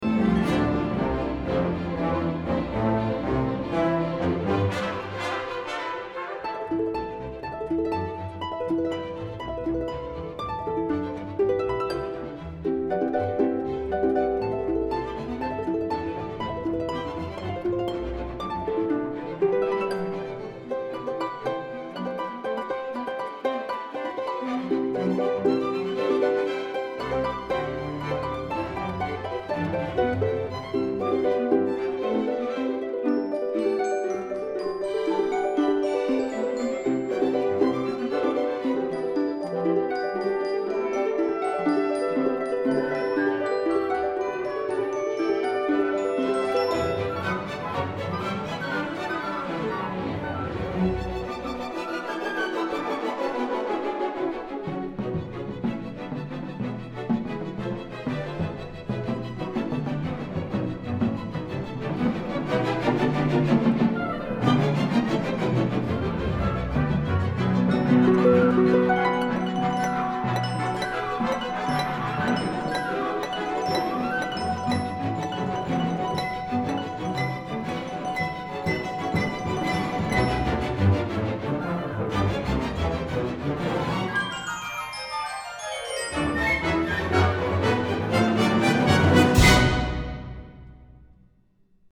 Instrumentation: harp soloist and orchestra